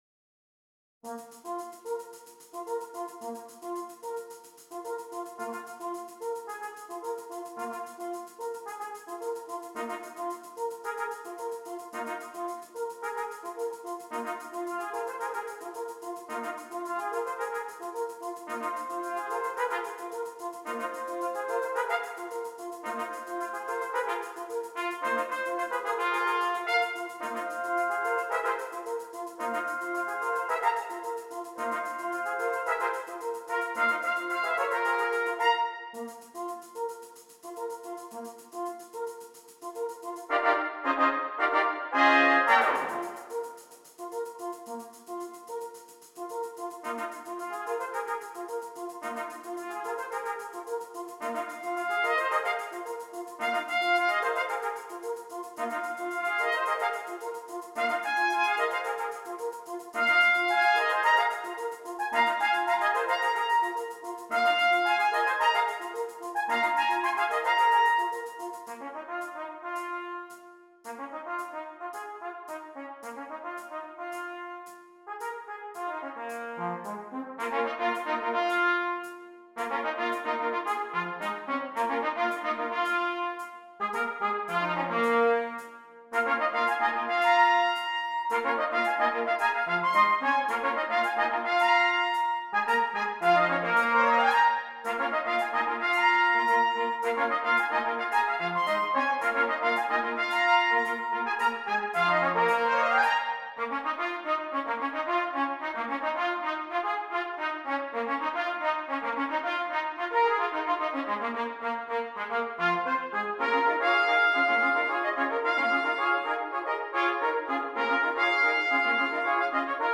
6 Trumpets
This would add a different colour to the bass line.